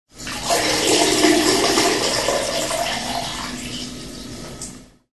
На этой странице собраны различные звуки мочеиспускания в высоком качестве.
Звук девушки ссущей в унитаз